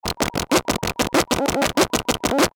标签： 电路弯曲的 复杂的 自制 LOFI 噪声
声道立体声